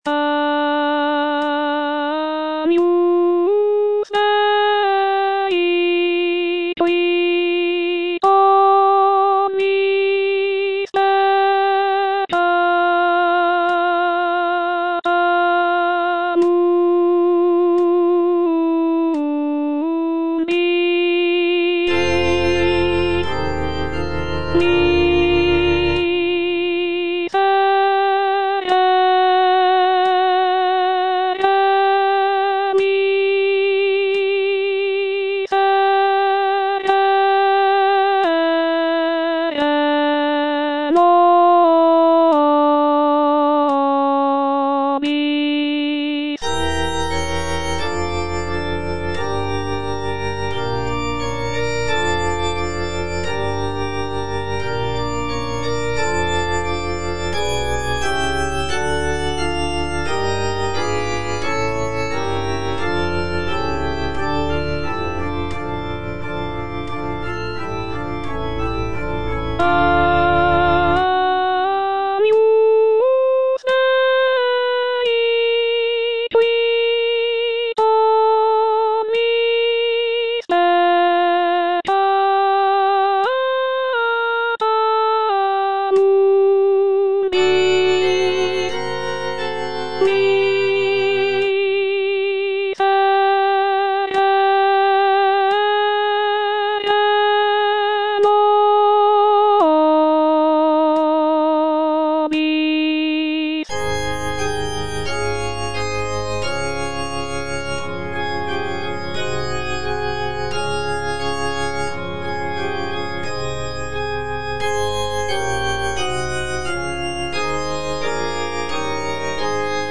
A. BRUCKNER - MISSA SOLEMNIS WAB29 11. Agnus Dei - Alto (Voice with metronome) Ads stop:
"Missa solemnis WAB29" is a sacred choral work composed by Austrian composer Anton Bruckner in 1854.